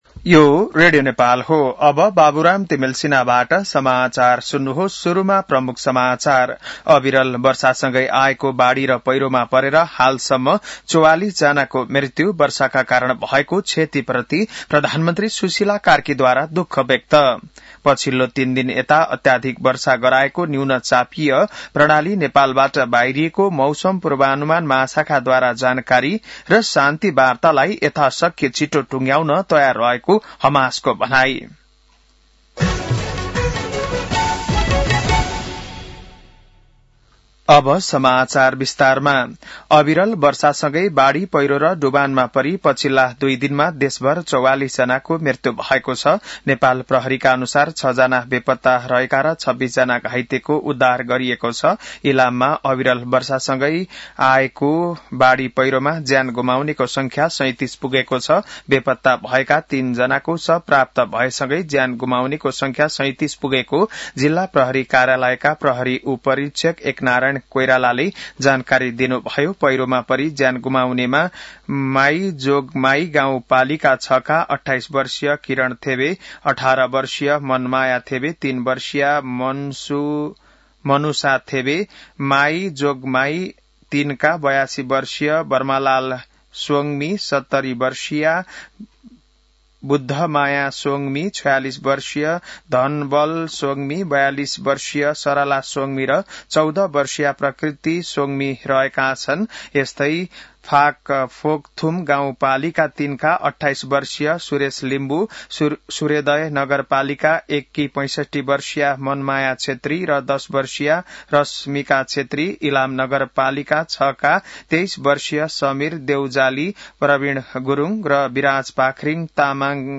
An online outlet of Nepal's national radio broadcaster
बिहान ९ बजेको नेपाली समाचार : २७ जेठ , २०८२